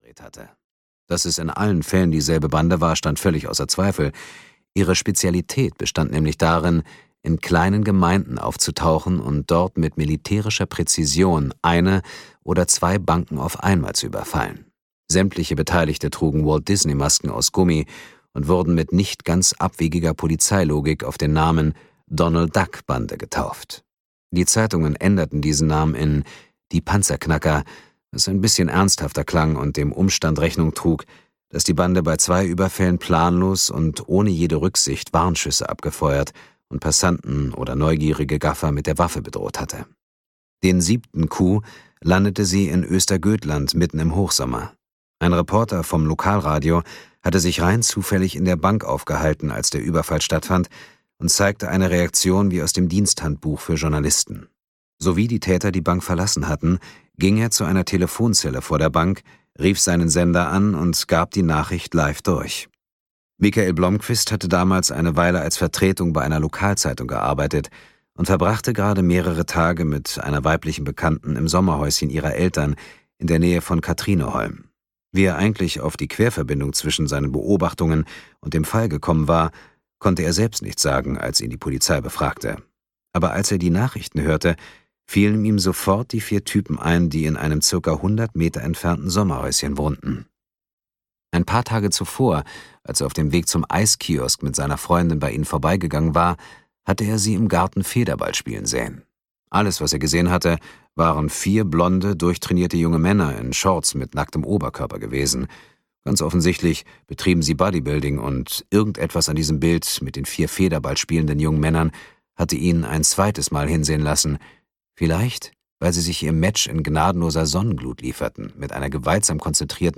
Audio kniha
Ukázka z knihy
• InterpretDietmar Wunder